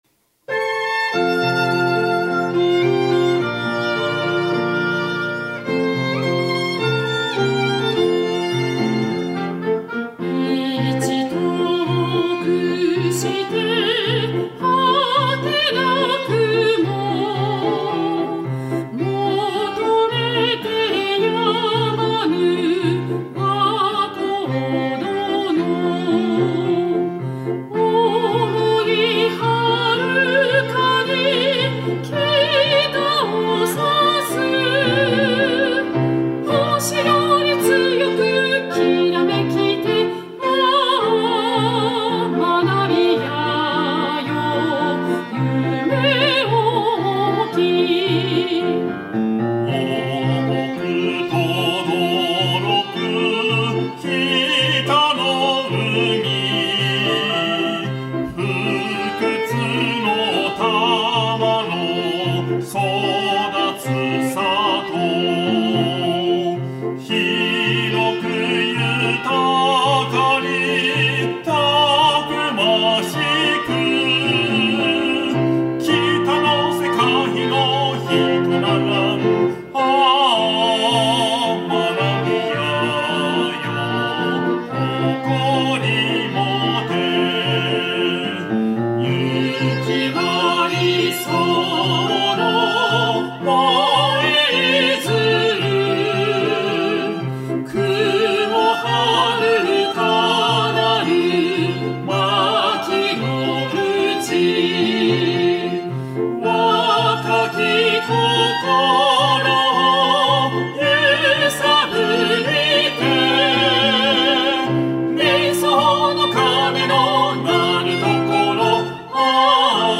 校歌 校歌（R5.03 アンサンブルグループ奏楽）.mp3 ↑クリックすると校歌が流れます。